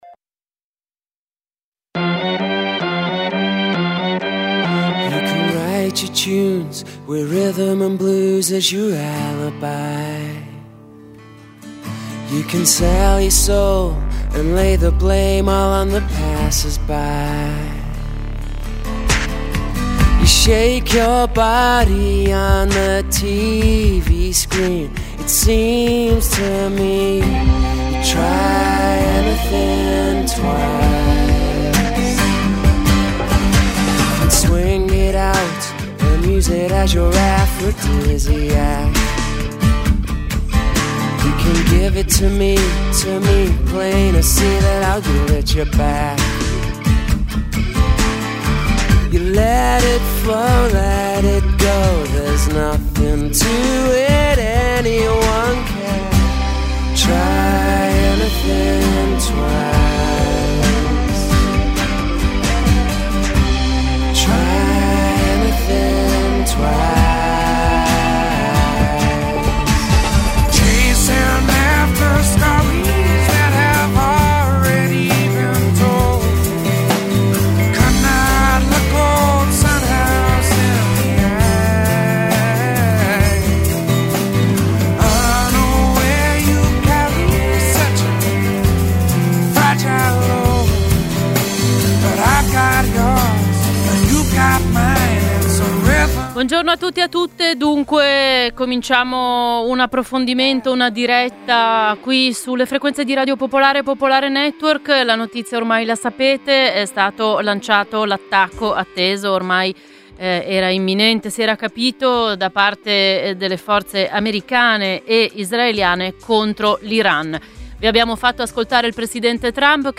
Microfono aperto